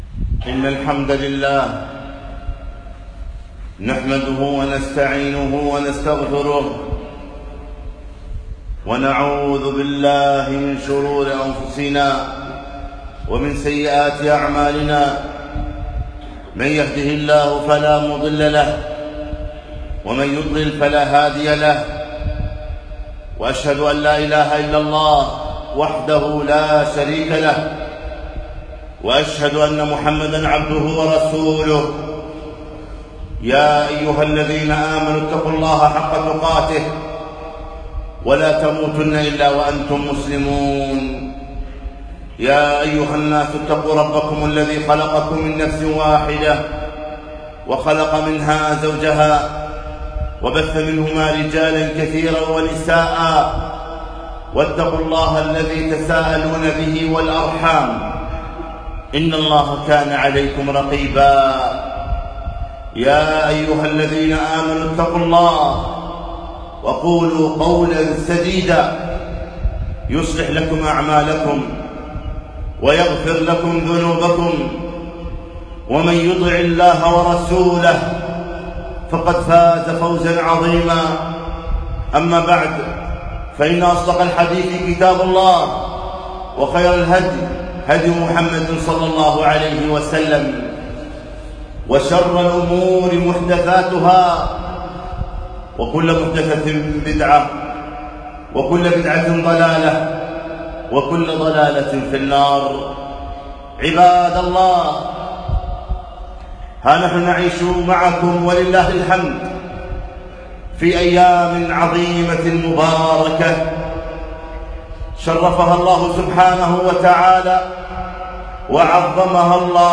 خطبة - توبوا إلى الله جميعا أيها المؤمنون لعلكم تفلحون - دروس الكويت